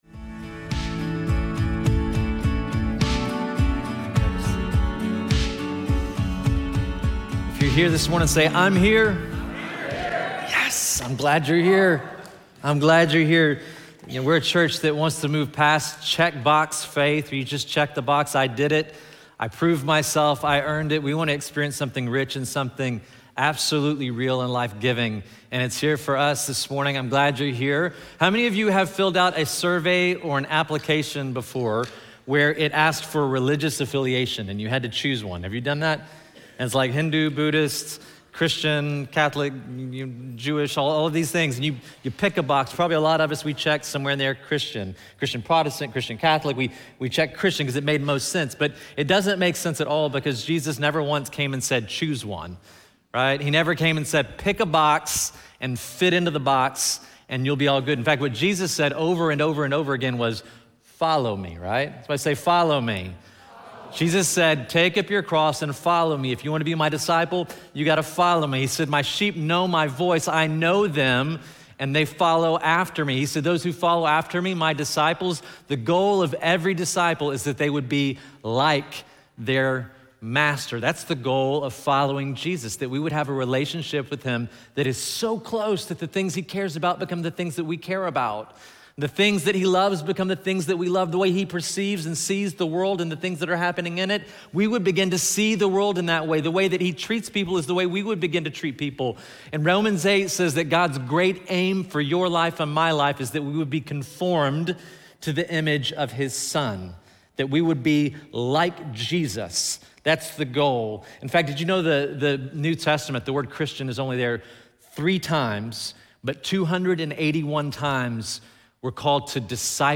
feb-16-sermon-audio.mp3